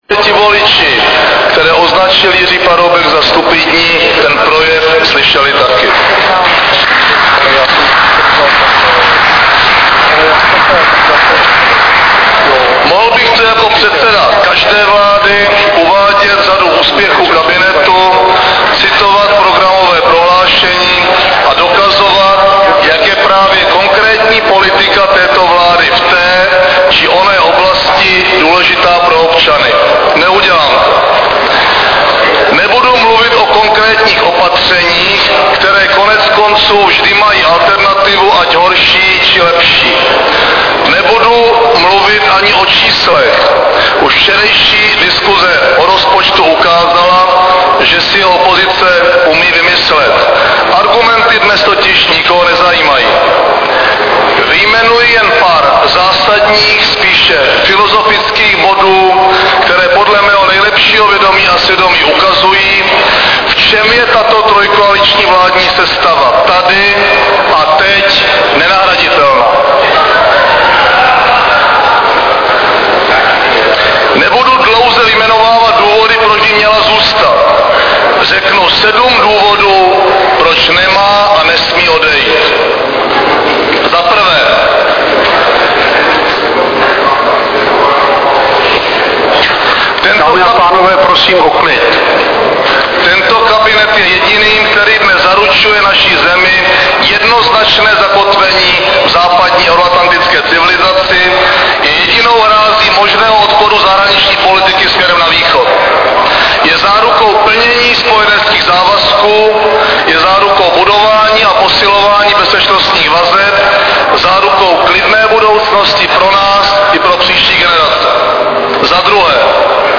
Projev předsedy vlády Mirka Topolánka na půdě Poslanecké sněmovny
Projev předsedy vlády Mirka Topolánka přednesený na půdě Poslanecké sněmovny dne 22. října 2008
Zvukový záznam projevu premiéra Mirka Topolánka